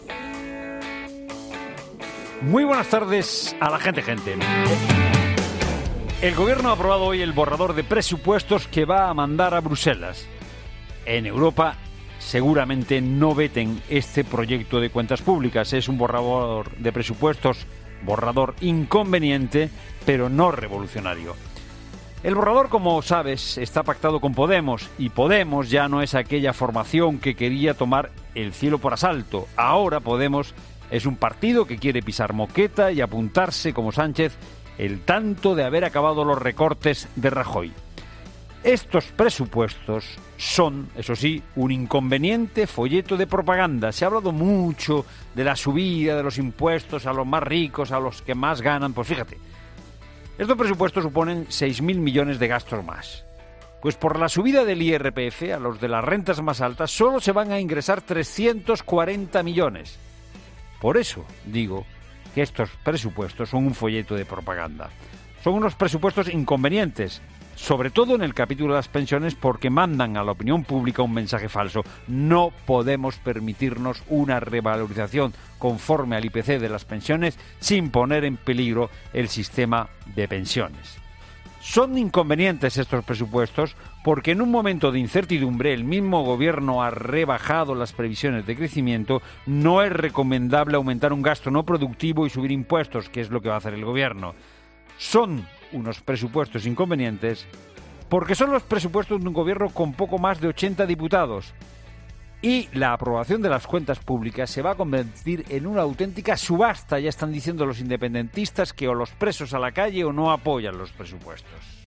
Monólogo de Fernando de Haro de las 16h.